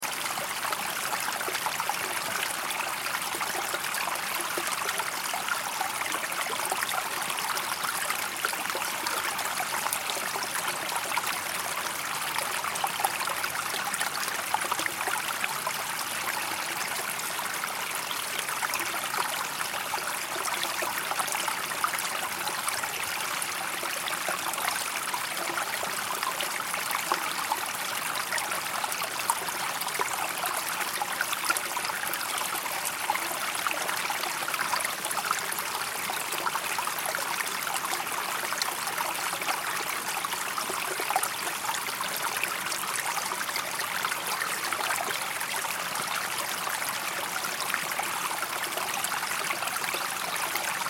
دانلود آهنگ آب 75 از افکت صوتی طبیعت و محیط
دانلود صدای آب 75 از ساعد نیوز با لینک مستقیم و کیفیت بالا
جلوه های صوتی